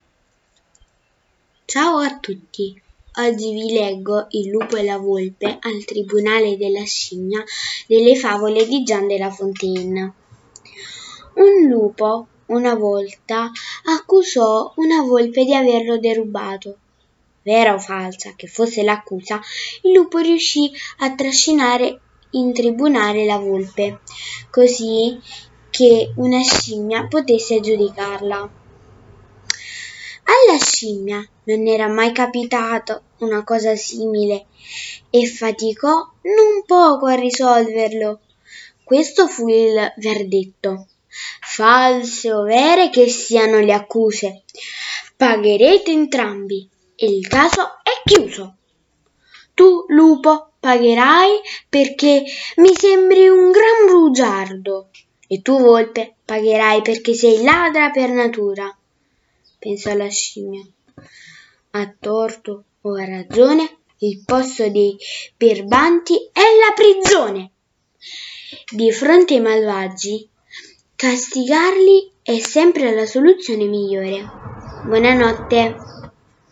Oggi vi leggo il racconto di Jean de la Fontaine ” Il lupo e la volpe al tribunale della scimmia “.